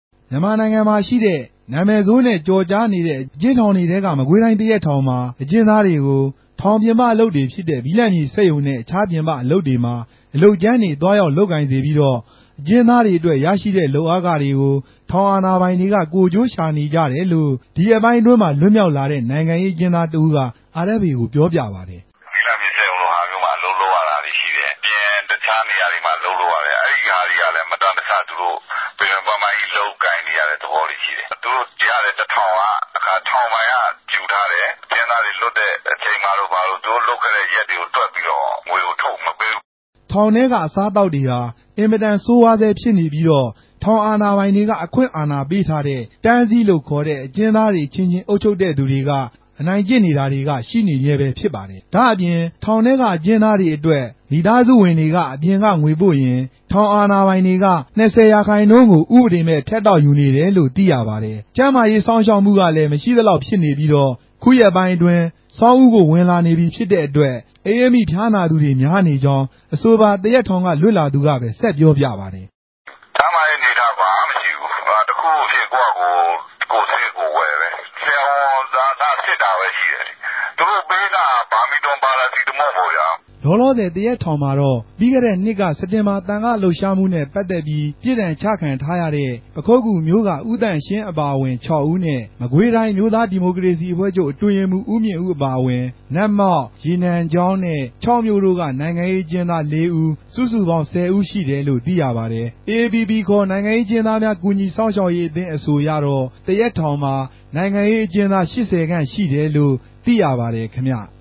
သတင်းပေးပိုႛခဵက်။